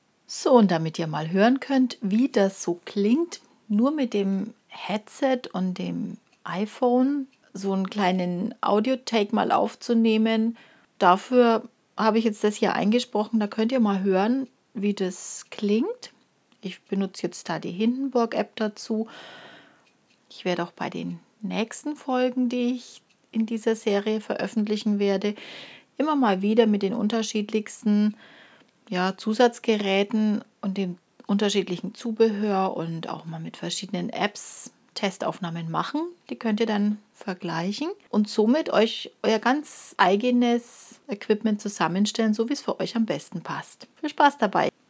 Testaufnahme mit iPhone 4S, Headset, App Hindenburg
Audio-Test Headset
GelbeTasche-Test-Headset.mp3